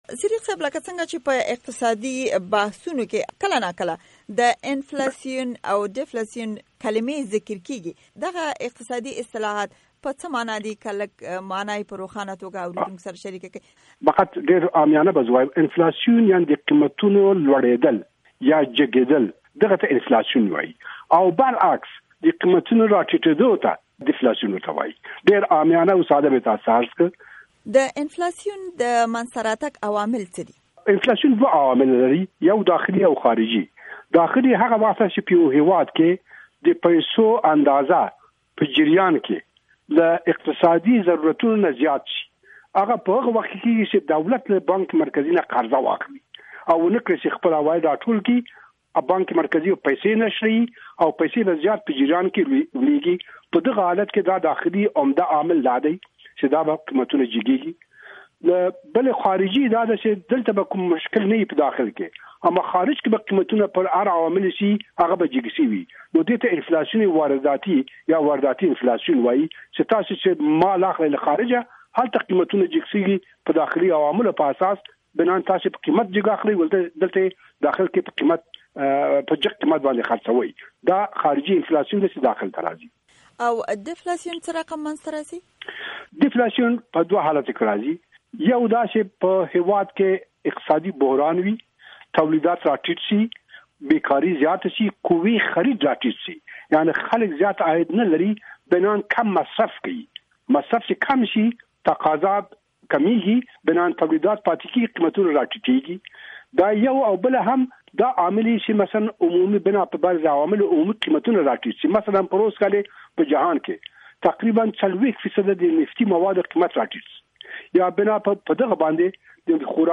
مرکې
د افغانستان بانک د رئيس خليل الله صديق سره بشپړه مرکه